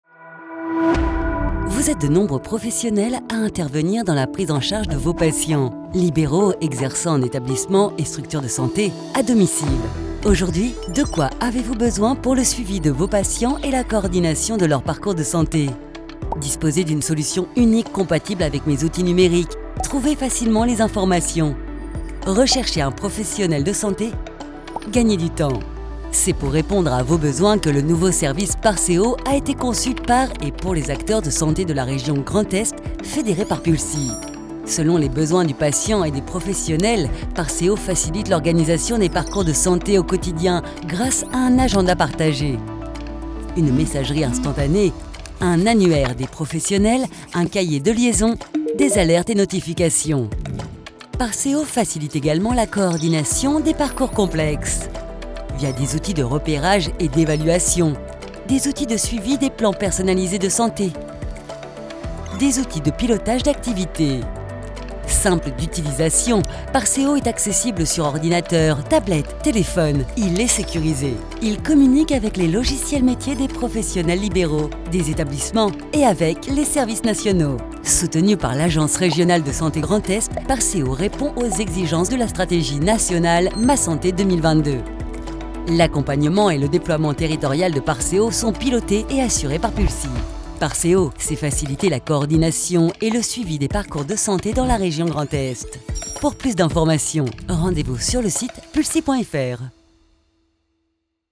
Natürlich, Zuverlässig, Erwachsene, Freundlich
Erklärvideo
She works from her personal studio so that your projects are recorded in the best possible quality.